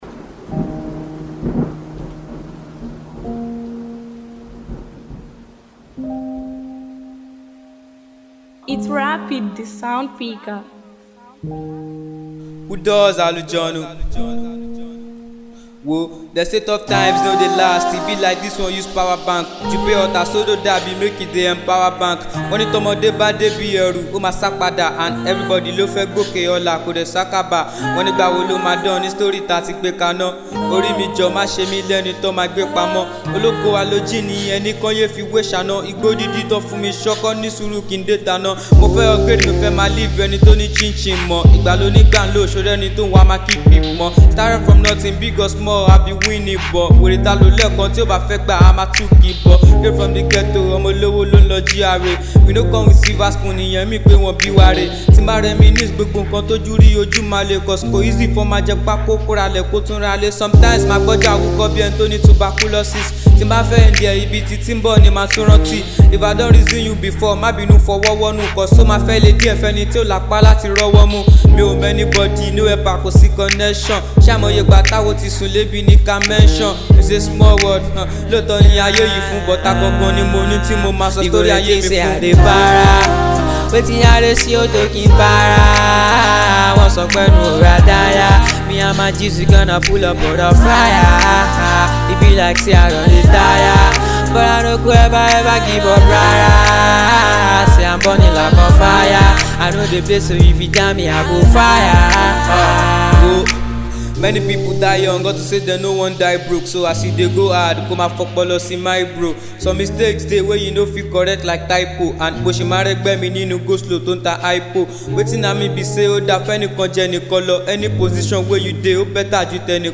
Brand new melodious tune
this amazing rapper